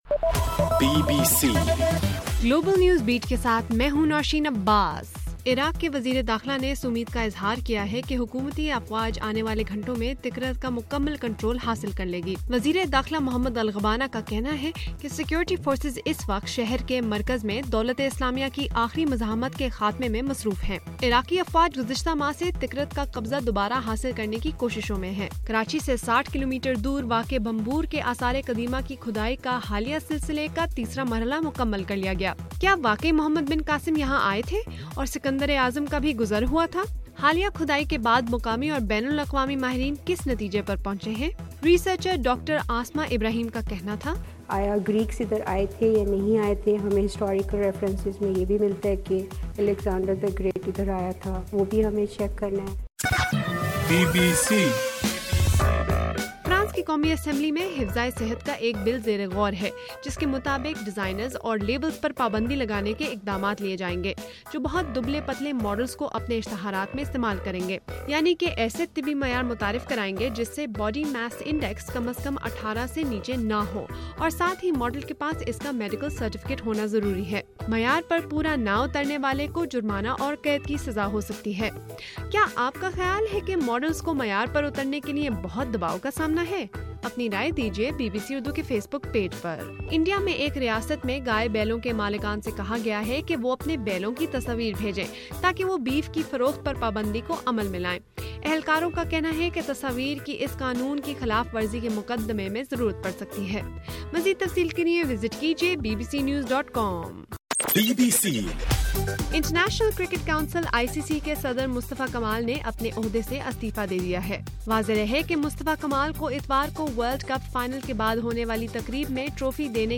اپریل 1: رات 10 بجے کا گلوبل نیوز بیٹ بُلیٹن